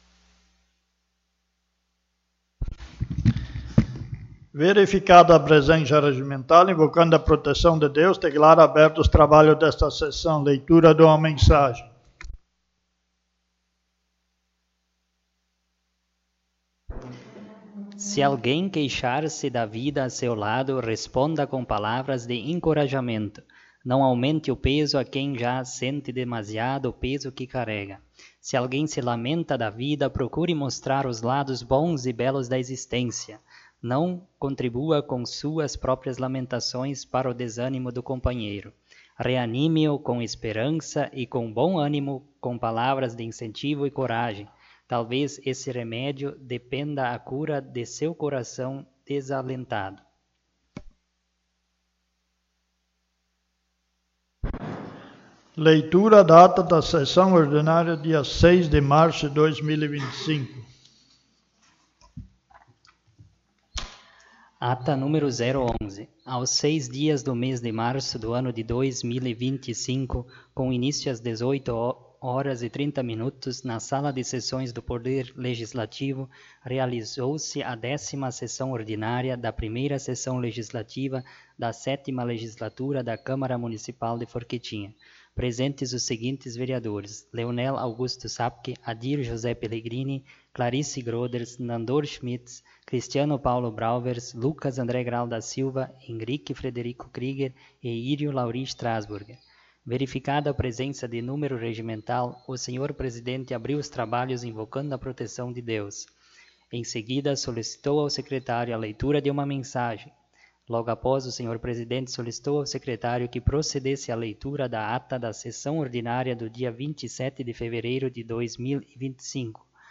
11ª Sessão Ordinária
O espaço da tribuna foi utilizado pelos vereadores Leonel Augusto Sabke, Nandor Schmitz, Décio Picoli e Lucas A. G. da Silva.